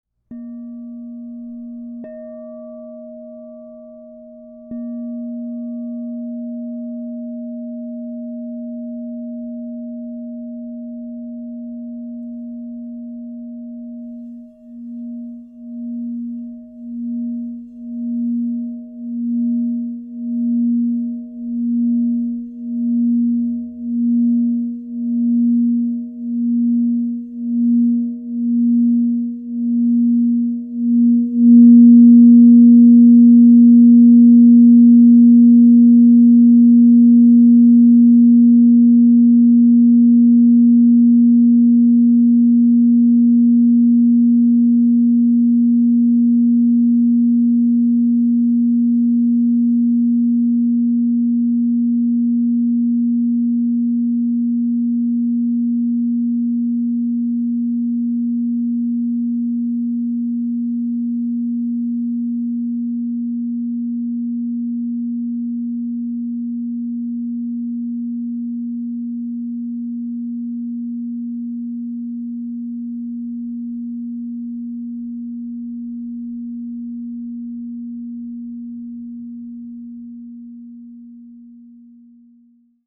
Crystal Tones® Aqua Aura Gold 9 Inch A# Singing Bowl
Immerse yourself in the radiant energy of the Crystal Tones® Aqua Aura Gold 9 inch A# Singing Bowl, resonating at A# +30 to inspire spiritual clarity, emotional renewal, and inner harmony.
The expansive 9-inch size delivers rich and resonant tones, making it ideal for personal meditation, sound therapy, and enhancing sacred spaces.
Transform your practice with 9″ Crystal Tones® alchemy singing bowl made with Aqua Aura Gold in the key of A# +30.
528Hz (+)